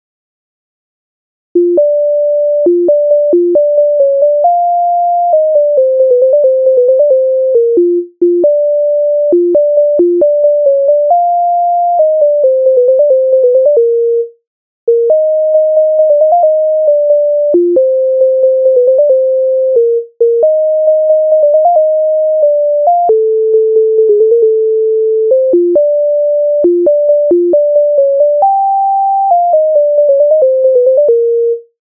MIDI файл завантажено в тональності B-dur